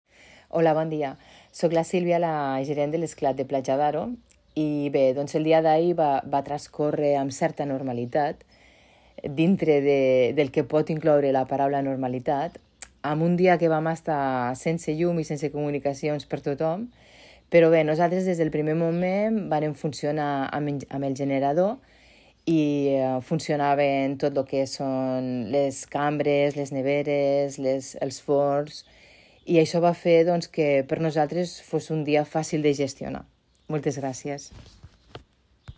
Al Supermatí hem fet un programa especial per poder recollir els testimonis d'alcaldes, ciutadans i empresaris de la comarca per veure com van afrontar les hores sense llum d'aquest dilluns